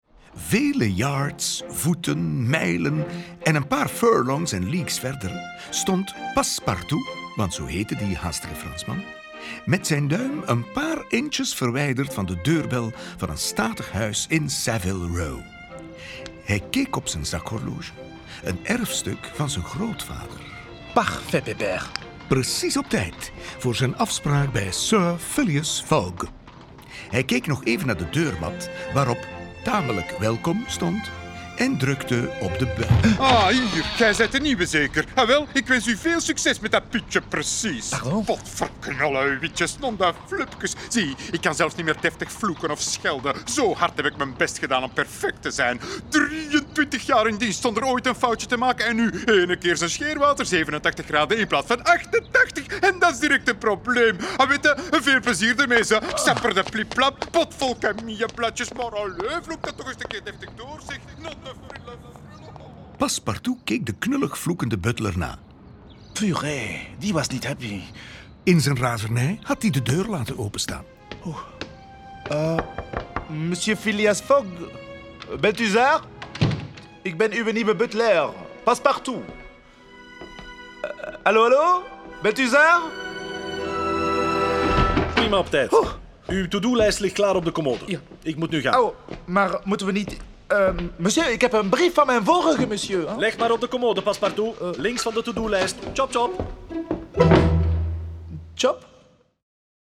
Ze worden verteld, gespeeld en gezongen door Vlaanderens meest getalenteerde acteurs en zitten boordevol humor, liedjes en knotsgekke geluidseffecten.